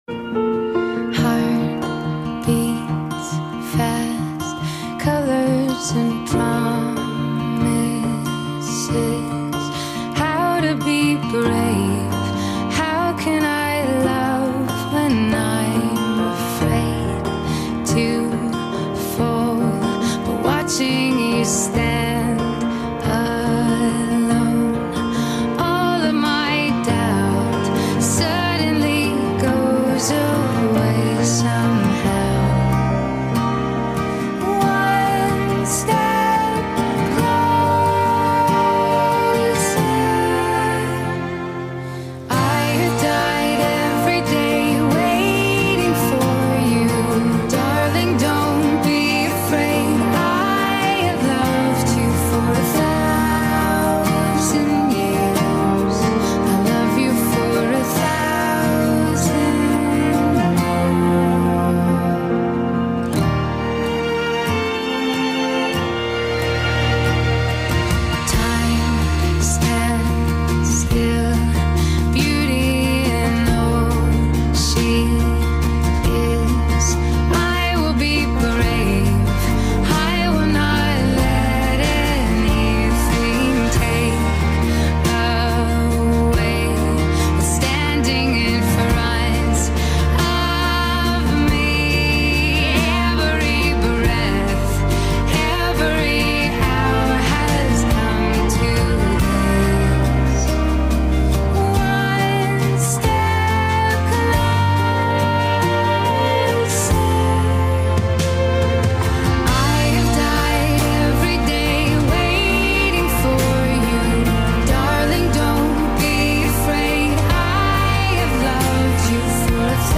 Fireworks on Clearwater Harbor, Fourth